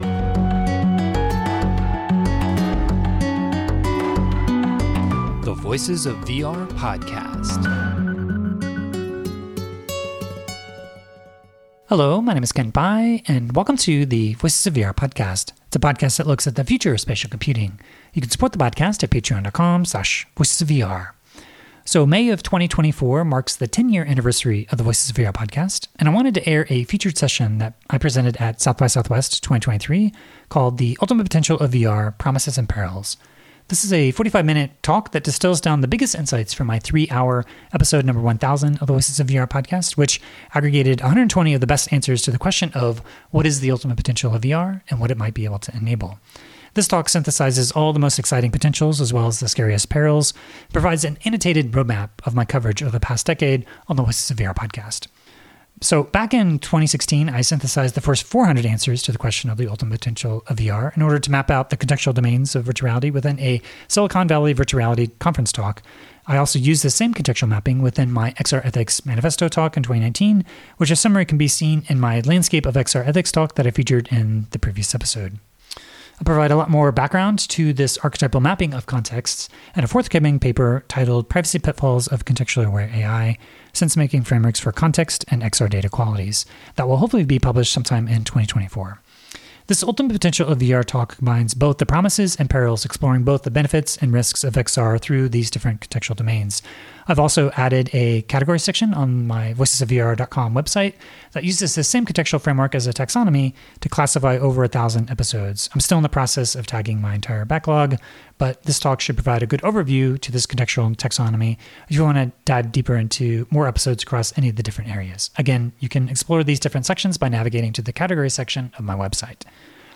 [Featured Session Presentation]. South by Southwest Festival, Austin, Texas, United States; Austin Convention Center, Room 16AB.↩